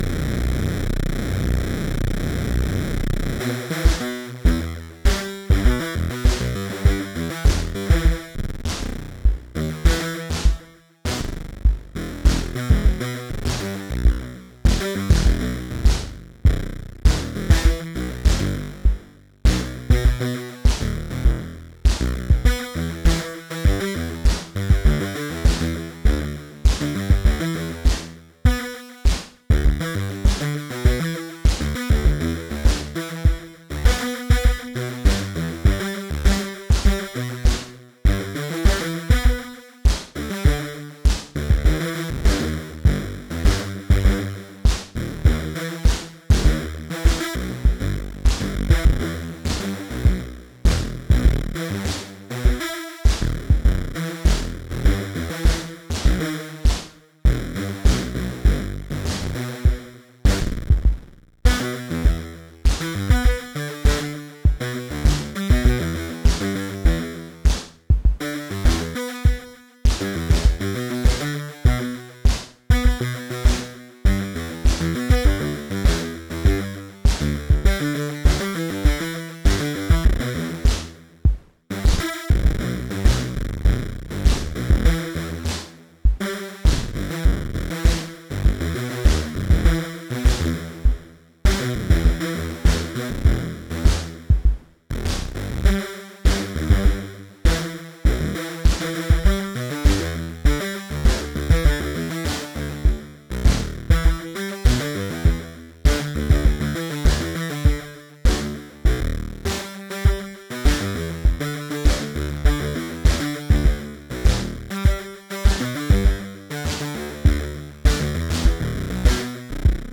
vcv-rack-pro audible-instruments drumsynth oppressor-pro prob-key gate-seq-64
This patch uses the "swarm" of seven sawtooth waves to produce a "supersaw" because I wanted to see what those sound like. I modulated the detuning of the saw waves with the Tides clone, which has a bit of a learning curve since it's extremely flexible.
This time around I used the Impromptu ProbKey sequencer to generate a couple of different chords probabilistically, and their GateSeq64 to add some spaces to the sequence. It was really easy to make something that sounded pretty cool, and I used the VCV native Seq-3 sequencer to add a simple toggle between an A and B part.